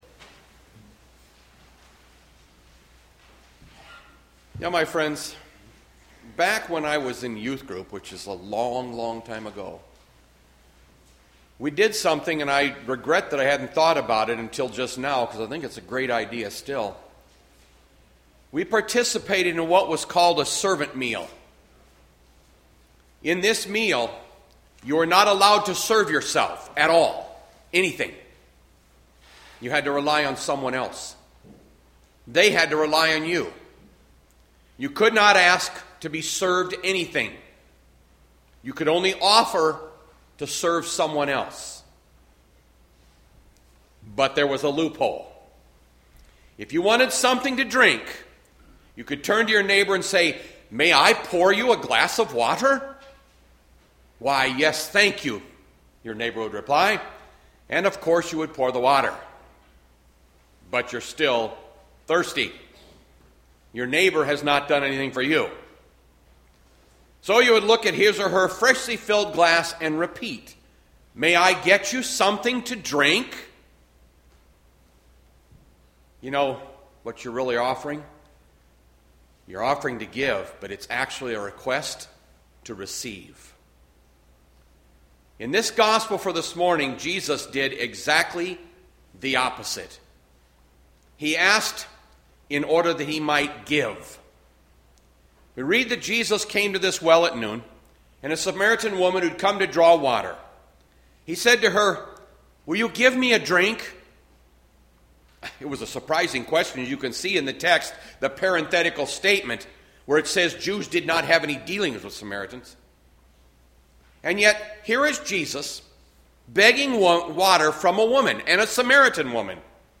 Bethlehem Lutheran Church, Mason City, Iowa - Sermon Archive Mar 15, 2020